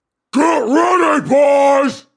но и чудесные голоса зеленокожих